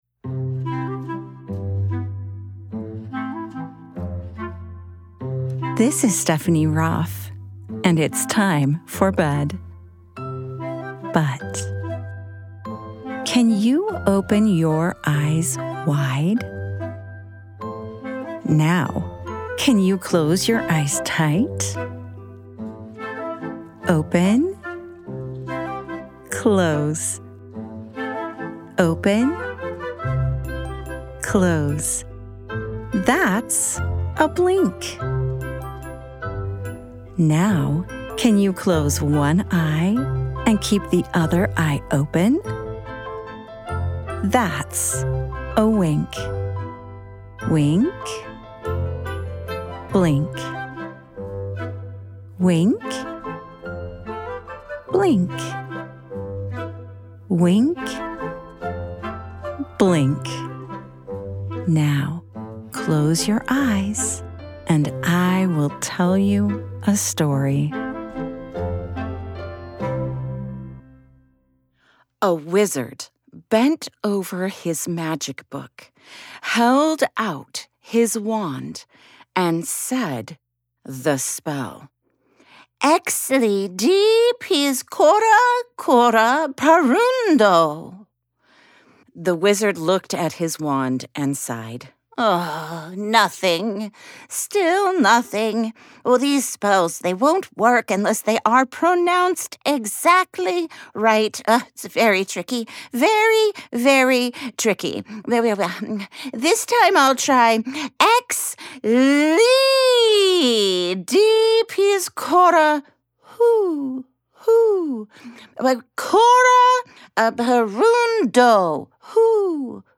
With playful sound effects, silly spells, and plenty of “whoo whoo” fun, Too Many Owls is a family-friendly story podcast parents will love sharing with their little listeners that gently helps listeners understand the power of being grateful for what you've got because it could always be worse.
@ wink-bedtime-stories Wink is a production of BYUradio and is always ad and interruption free.